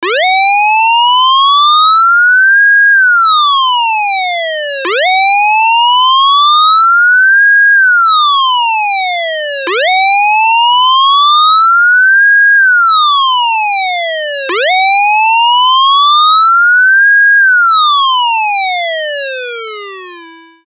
The pitch constantly rises and falls.
Wail
The wail has the longest cycle between high and low pitch.
siren-emergency-wail-1.mp3